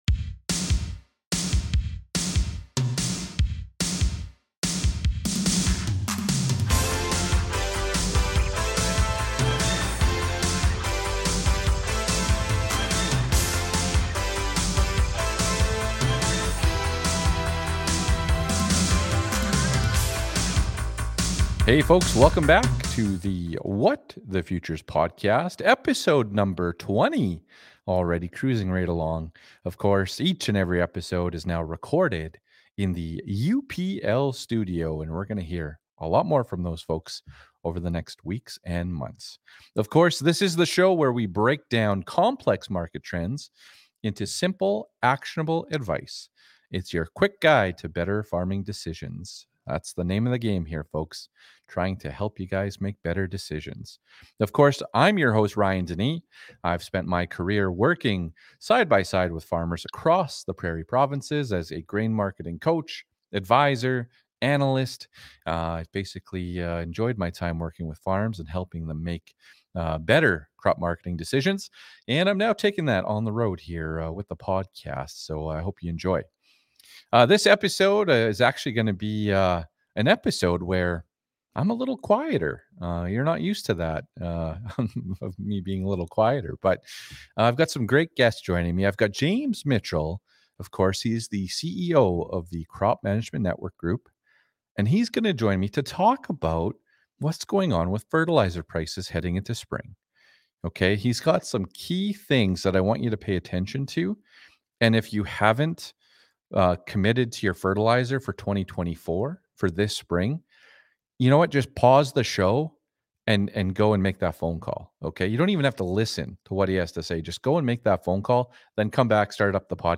Episode #20 was recorded in the UPL Studio!